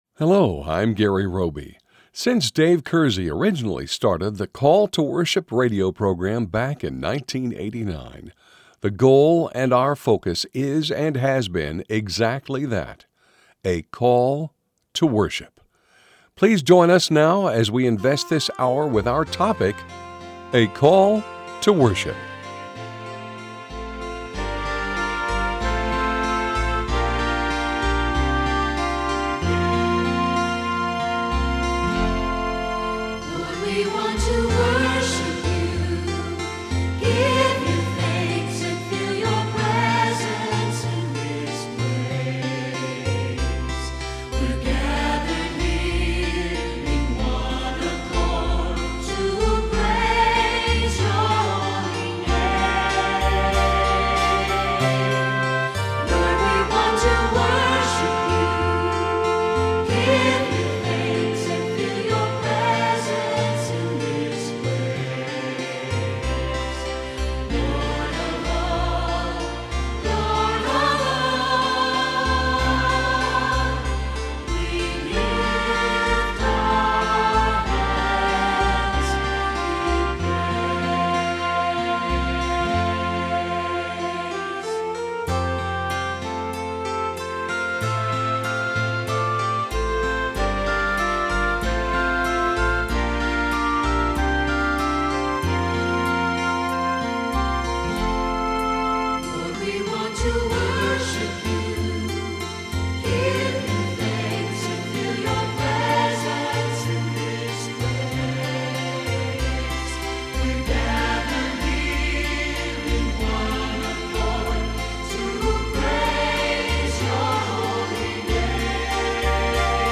For over 27 years now, that has been the invitation of this Call To Worship radio program and ministry. Think about what the concept of a Call To Worship means as we fellowship around the person and works of our God.
“WORSHIP” the words that you hear spoken and in song will become a ‘vehicle of worship’.